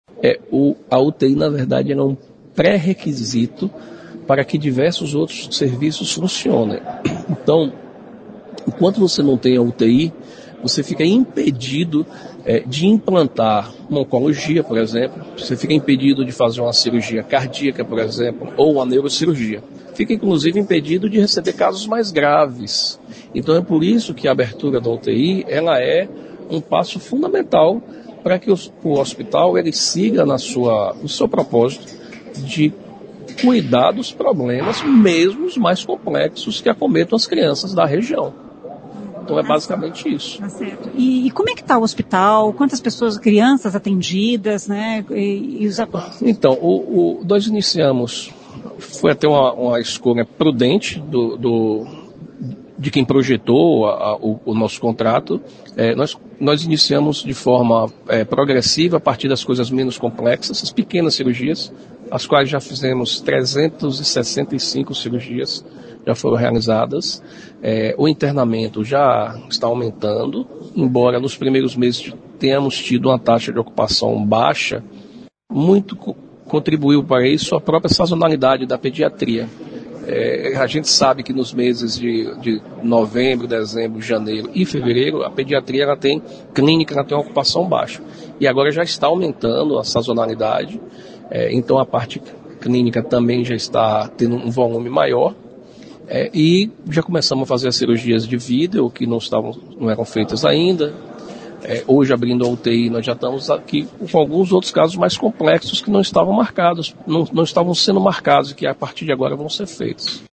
Cercada de assessores e de autoridades da cidade e do Paraná, a ministra disse que só na gestão dela o Ministério da Saúde já investiu quase 6 milhões de reais no hospital.[ouça o áudio]